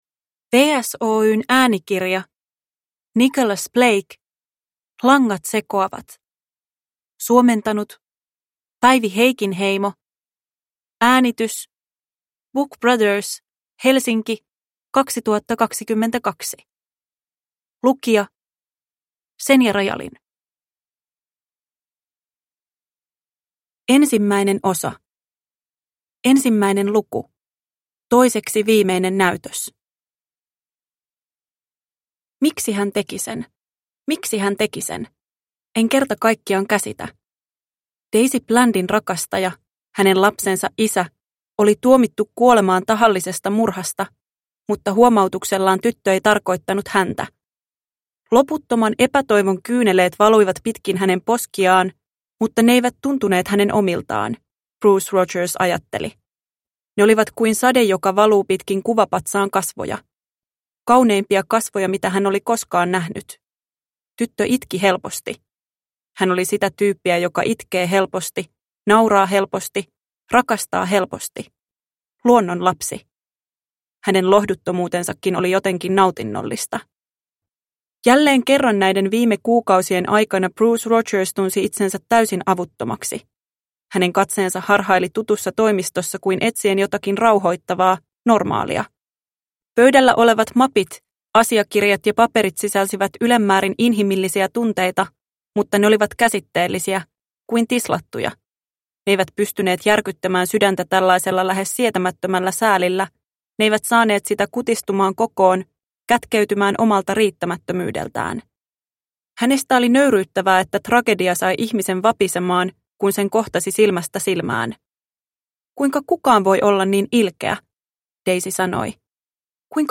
Langat sekoavat – Ljudbok – Laddas ner